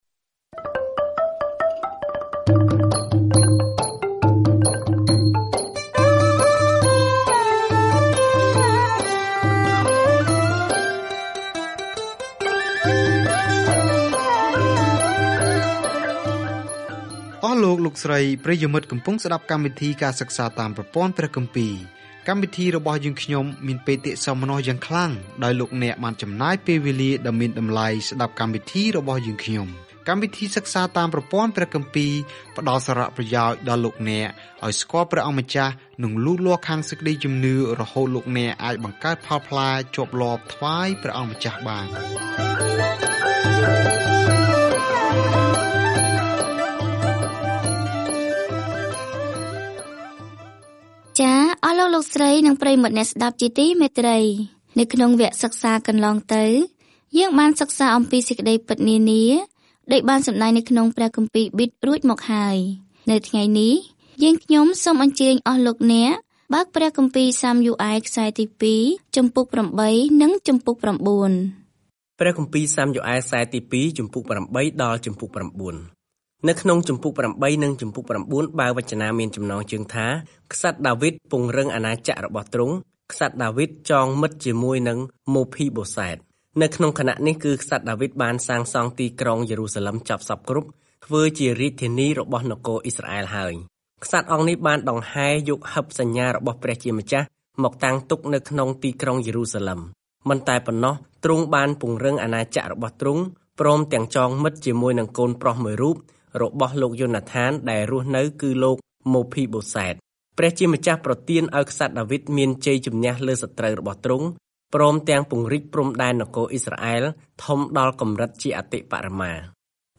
រឿងនៃទំនាក់ទំនងរបស់អ៊ីស្រាអែលជាមួយព្រះបន្តជាមួយនឹងការណែនាំរបស់ព្យាការីក្នុងបញ្ជីនៃរបៀបដែលព្រះភ្ជាប់ជាមួយនឹងរាស្ដ្ររបស់ទ្រង់។ ការធ្វើដំណើរប្រចាំថ្ងៃតាមរយៈ 2 សាំយូអែល នៅពេលអ្នកស្តាប់ការសិក្សាជាសំឡេង ហើយអានខគម្ពីរដែលជ្រើសរើសចេញពីព្រះបន្ទូលរបស់ព្រះ។